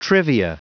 Prononciation du mot trivia en anglais (fichier audio)
Prononciation du mot : trivia